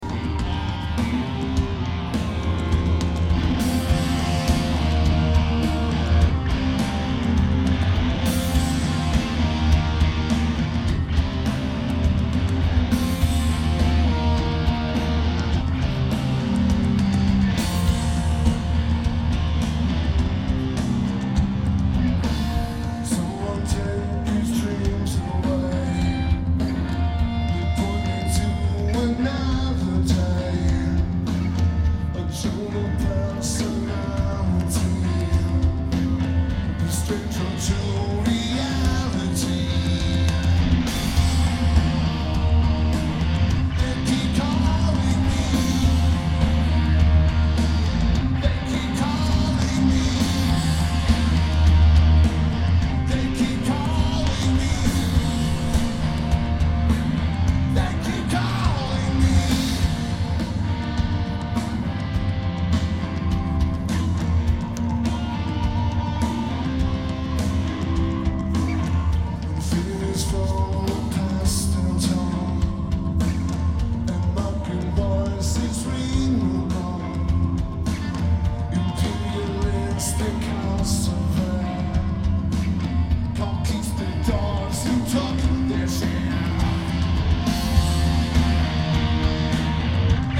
Fiddler's Green Amphitheater